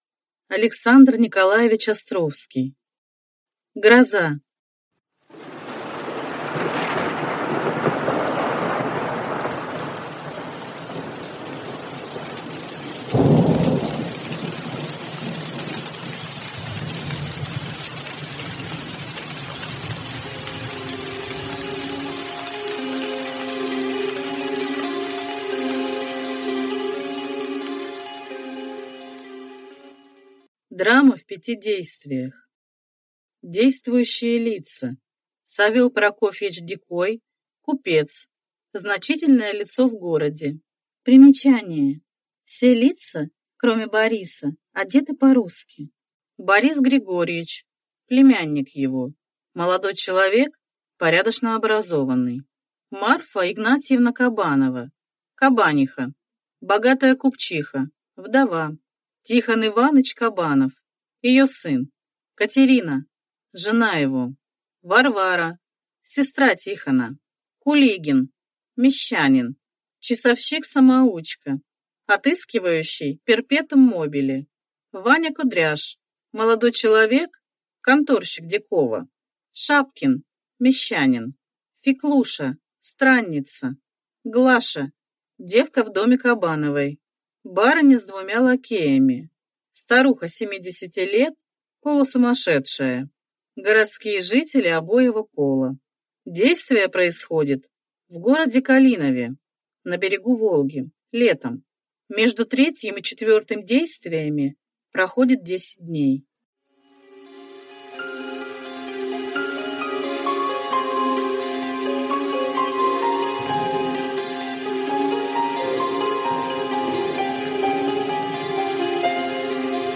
Аудиокнига Гроза | Библиотека аудиокниг